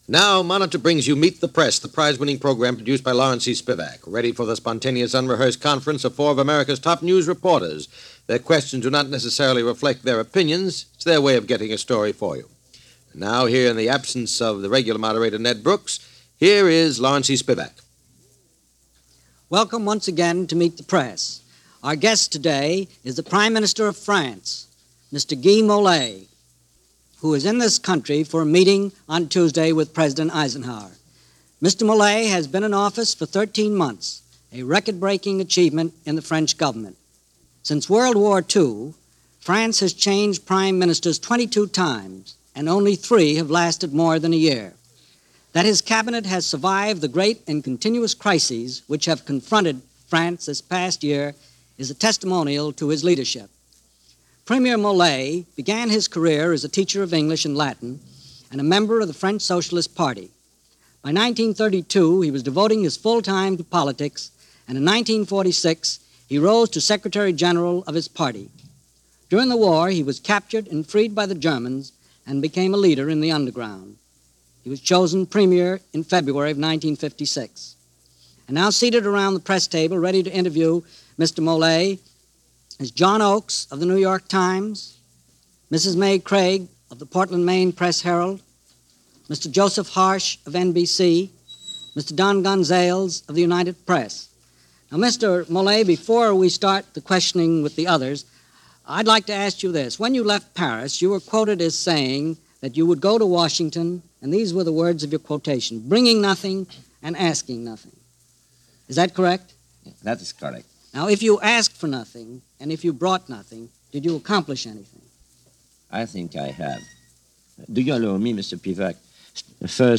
France In The 1950s' - An Interview With Prime Minister Guy Mollett - 1957 - Past Daily Reference Room
France In The 1950s - An Interview With Prime Minister Guy Mollett - 1957 - Meet The Press from March 15, 1957 - Past Daily Reference Room.
Meet-The-Press-Guy-Mollett-March-1957.mp3